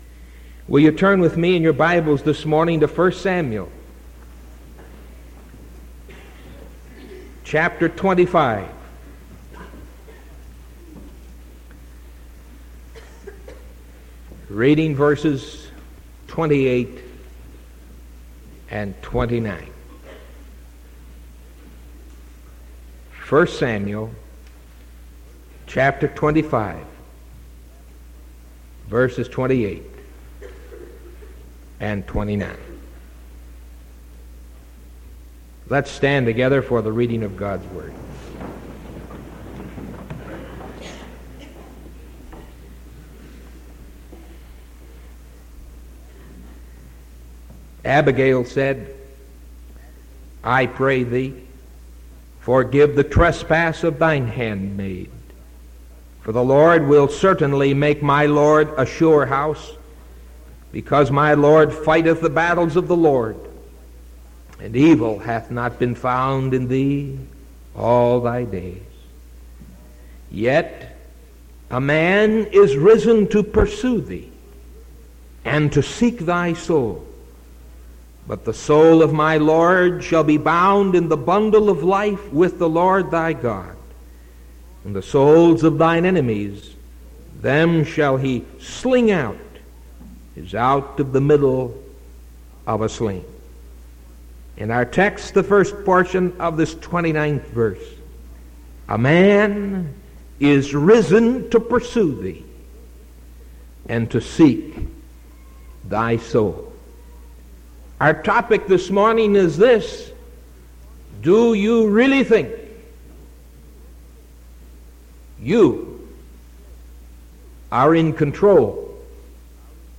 Sermon from September 29th 1974 AM